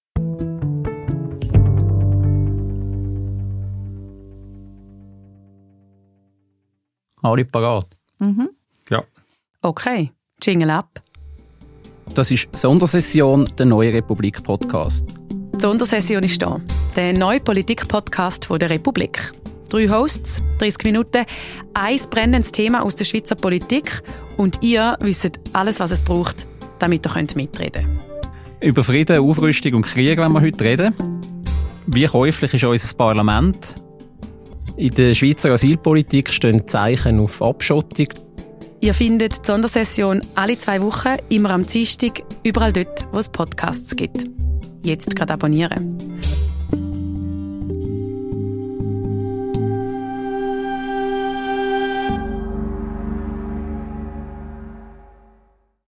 Drei Hosts, 30 Minuten, ein Thema aus der Schweizer Politik & ihr wisst alles, um mitreden zu können.